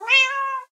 cat_meow3.ogg